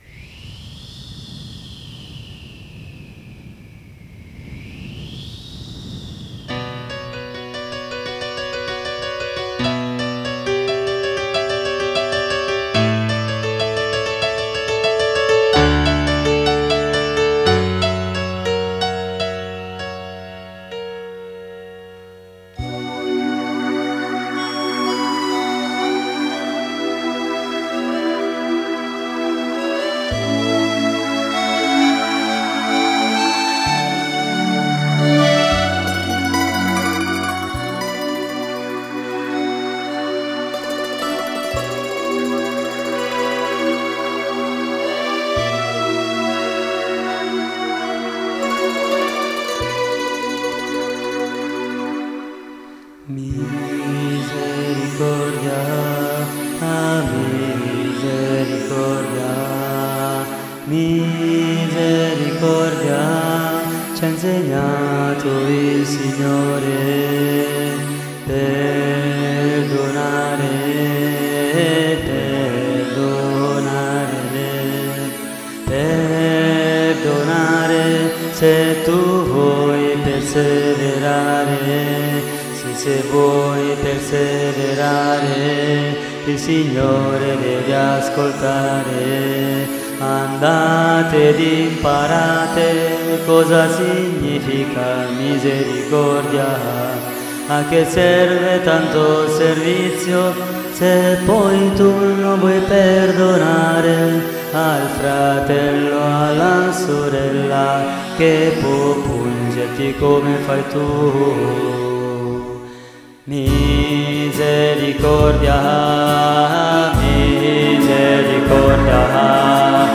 note di chitarra